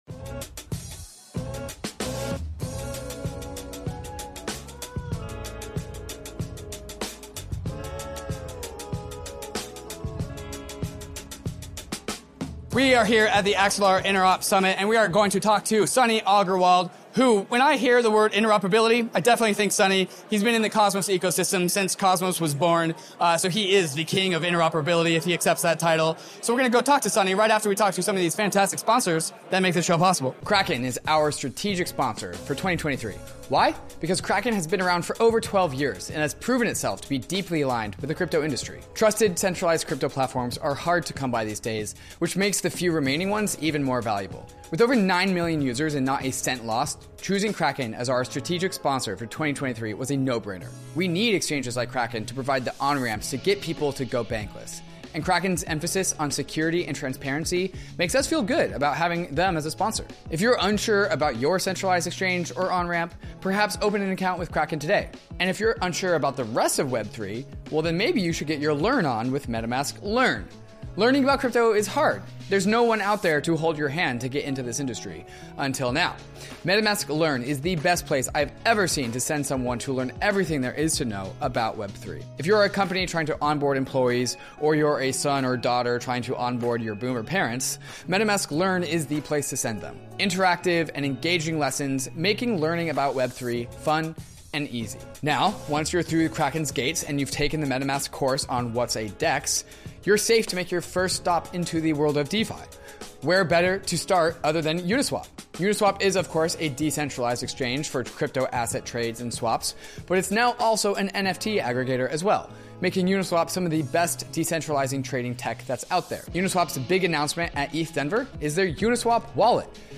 Sometimes, the frontier is at a crypto conference.